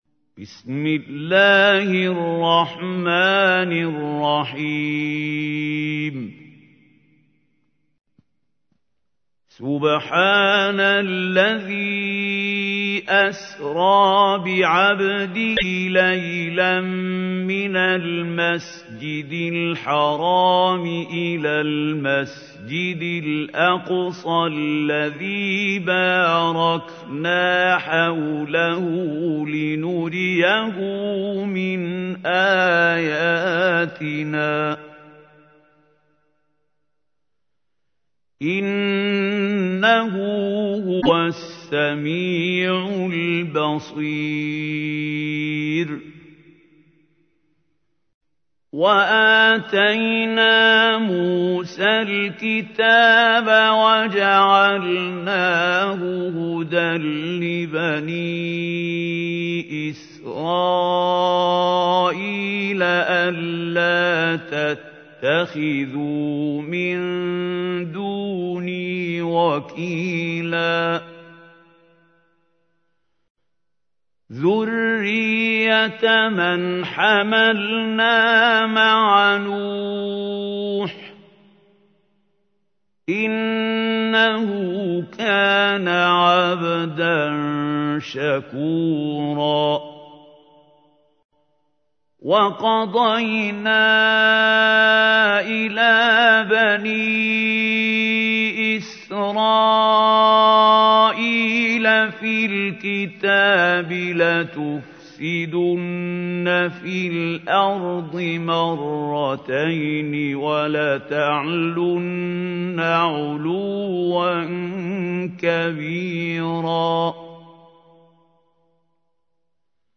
تحميل : 17. سورة الإسراء / القارئ محمود خليل الحصري / القرآن الكريم / موقع يا حسين